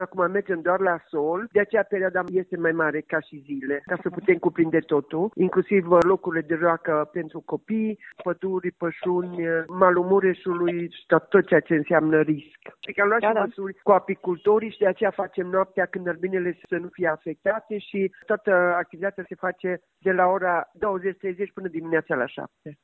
Primarul Maria Precup: